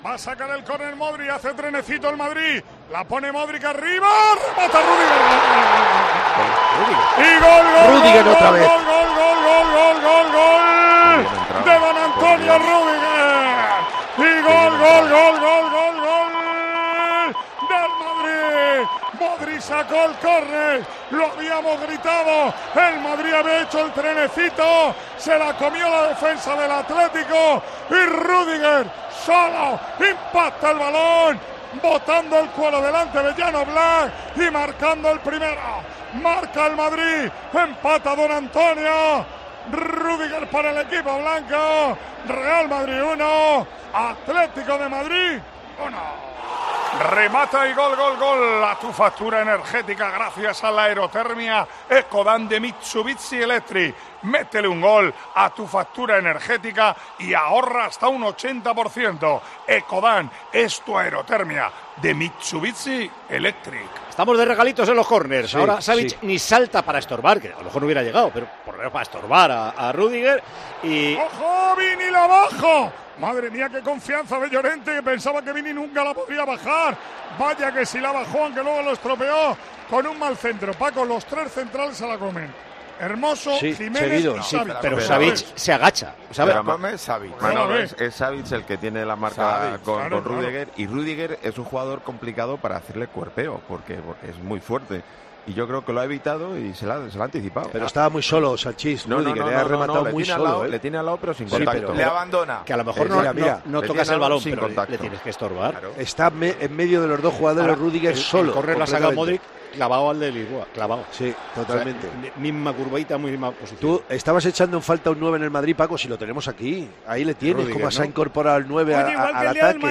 El equipo de COPE, en el Estadio Al-Awwal Park de Arabia Saudí
Así vivimos en Tiempo de Juego la retransmisión del Real Madrid - Atlético de Madrid